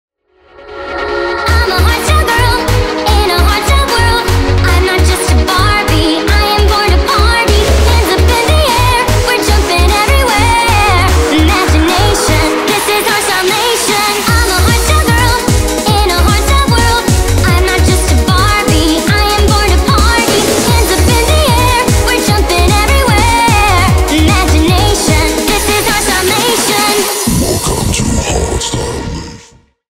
Ремикс
ритмичные